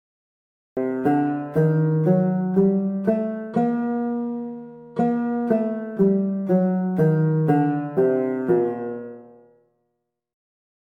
Arabic-scale_rahat_el_arouah.mp3